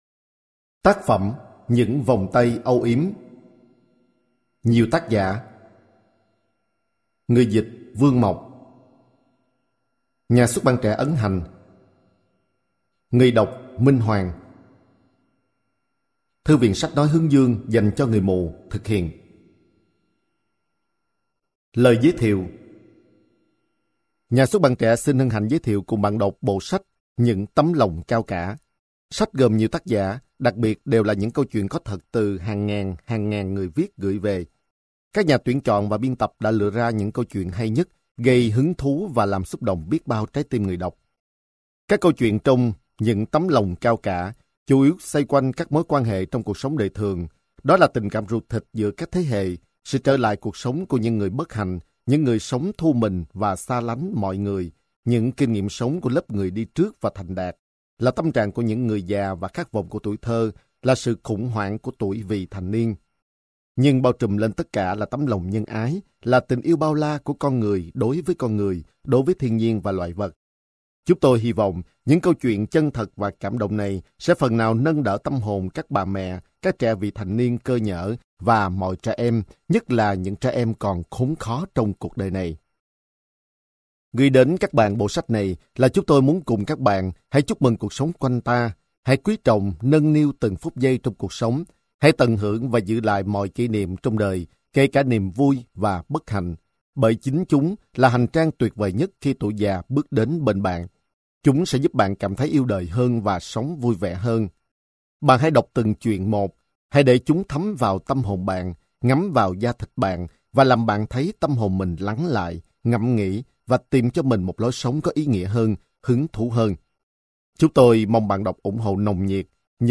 Sách nói Những Vòng Tay Âu Yếm - Sách Nói Online Hay